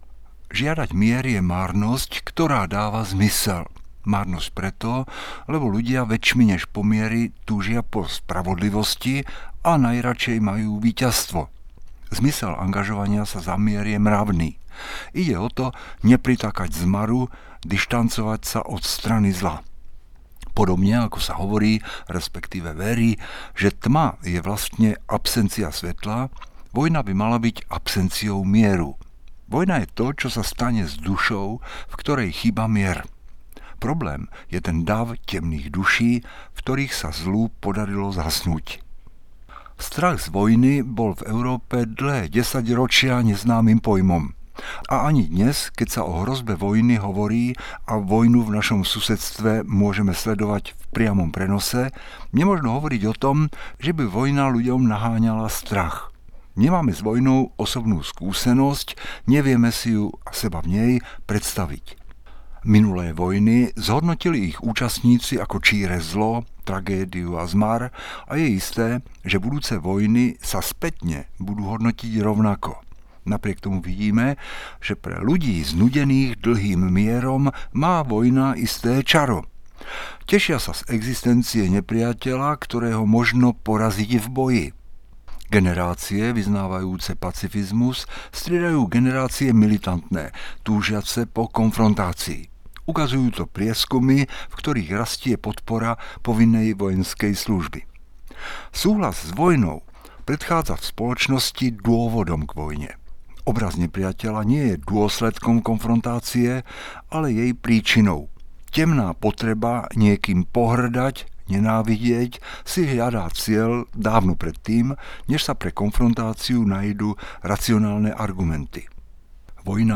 Príspevok na Oslavu 10. výročia iniciatívy Zjednotení za mier, hotel Devín, 13. máj 2025